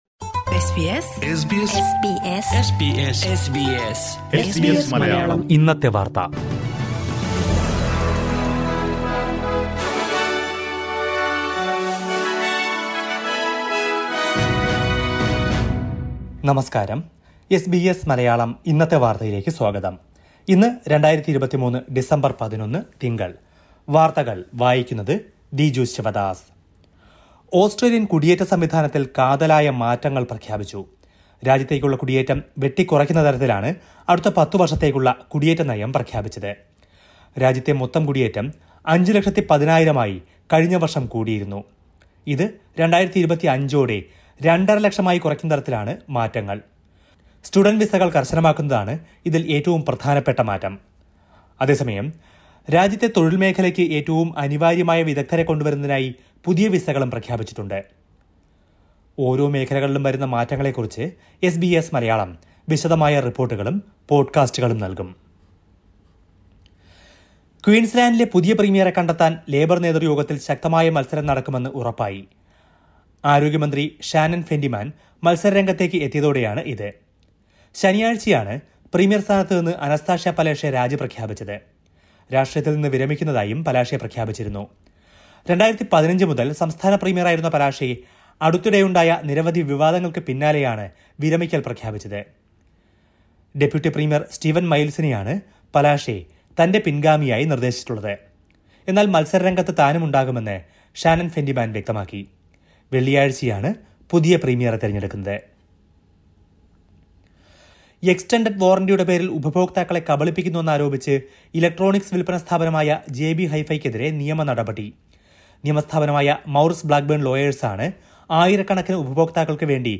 2023 ഡിസംബര്‍ 11ലെ ഓസ്‌ട്രേലിയയിലെ ഏറ്റവും പ്രധാന വാര്‍ത്തകള്‍ കേള്‍ക്കാം.